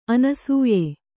♪ anasūye